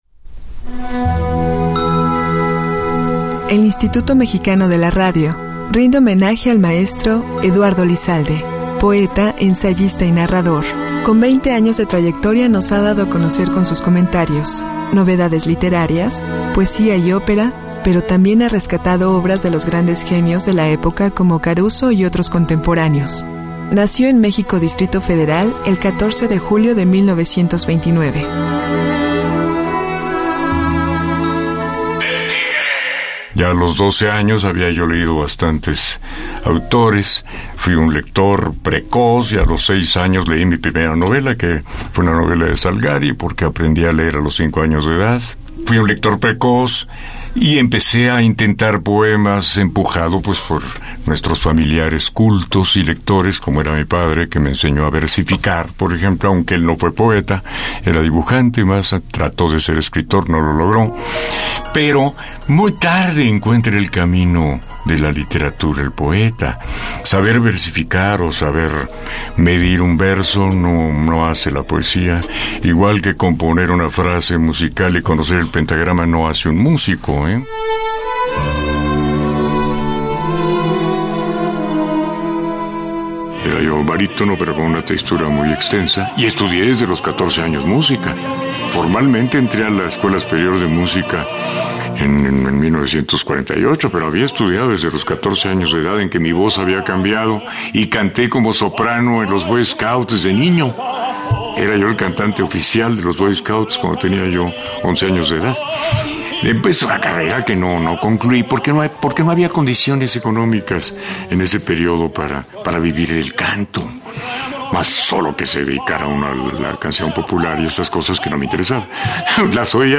Su conocimiento profundo sobre ópera y los más variados temas que conforman la cultura nacional y universal, su sensible e inigualable pluma, su rotunda y contundente voz, ensanchan las transmisiones de la radio pública y engalanan las emisoras del IMER, especialmente Opus 94.5 FM.